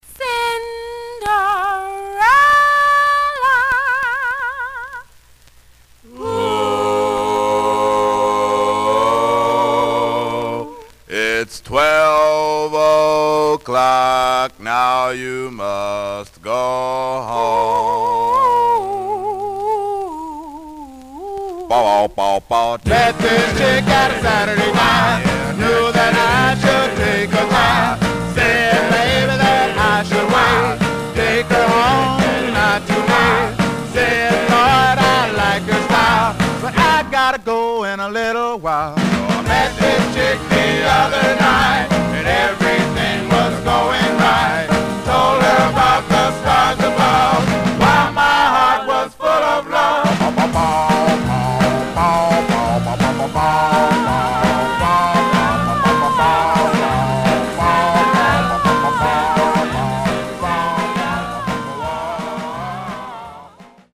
Some surface noise/wear Stereo/mono Mono
Male Black Group